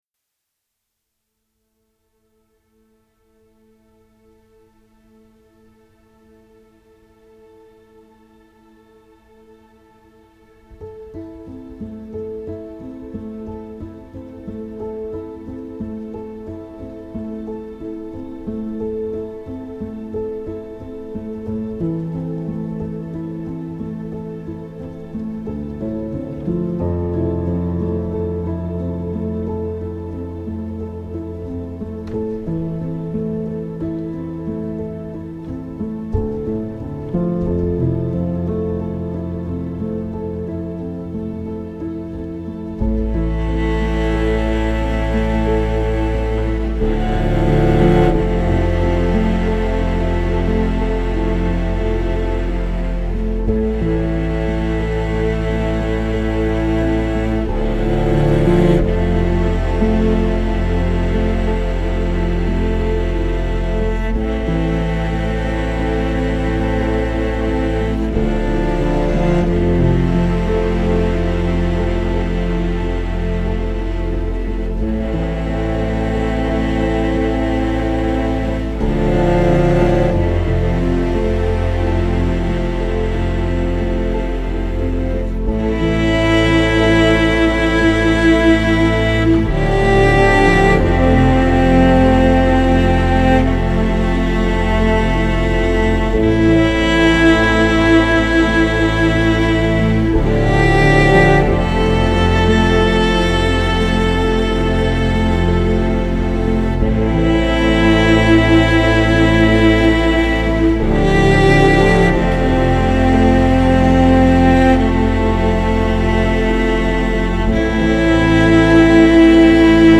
آرامش بخش الهام‌بخش پیانو عصر جدید موسیقی بی کلام